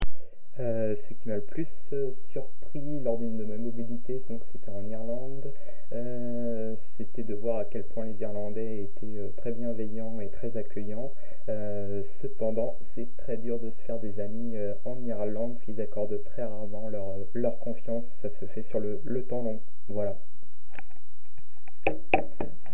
Cabine de témoignages
Témoignage du 17 novembre 2025 à 14h59